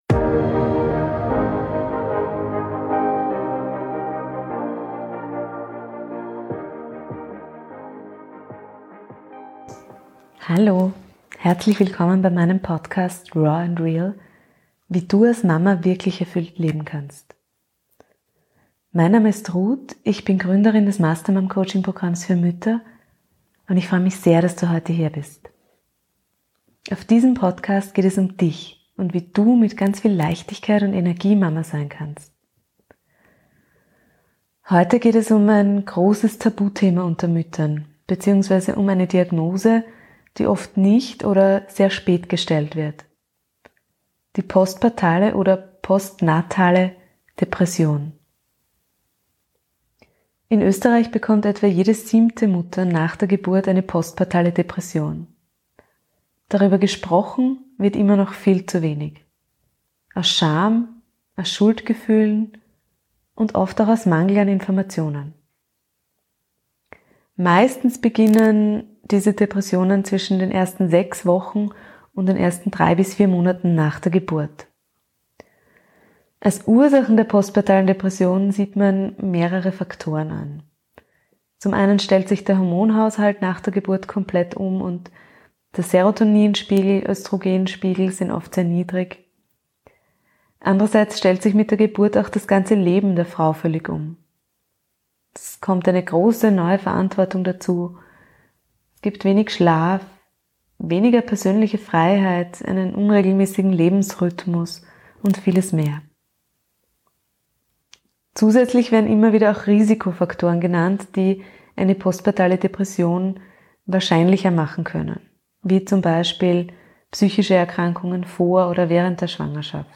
#29 Postpartale Depressionen - Interview mit einer betroffenen Mama ~ raw and real Podcast